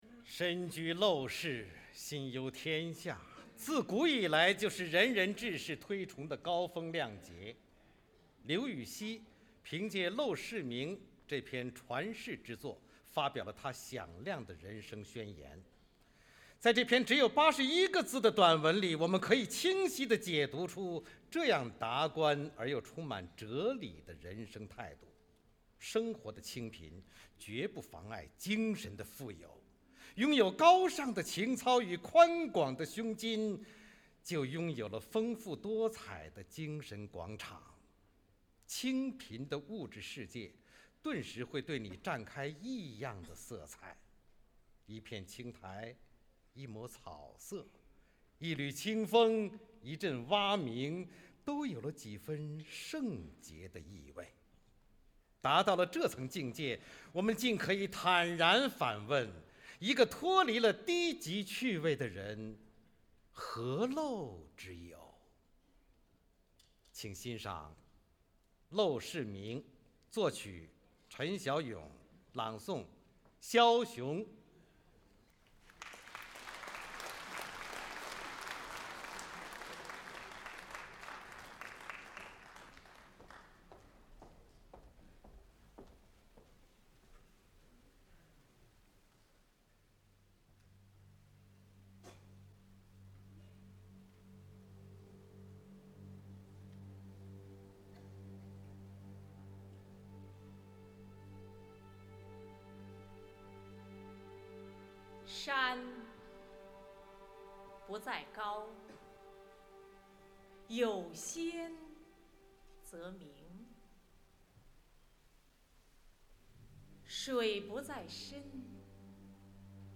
肖雄朗诵：《陋室铭》(（唐）刘禹锡)
解说词